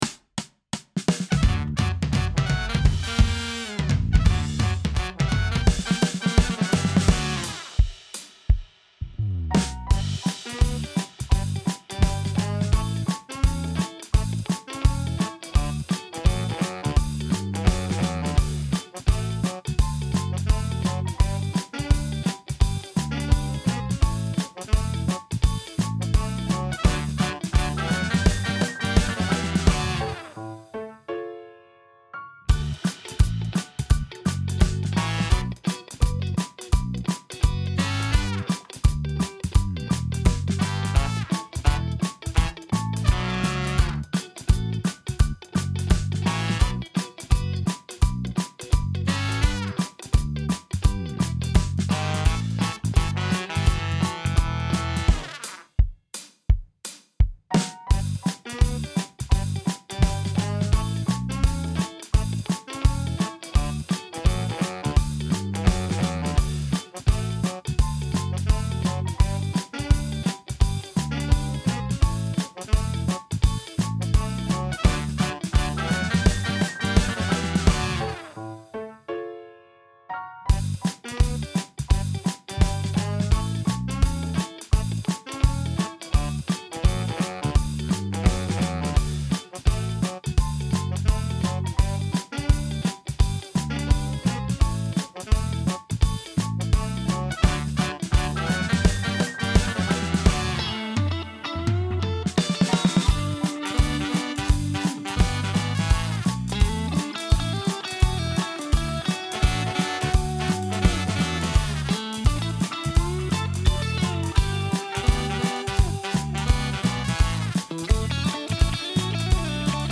Swing style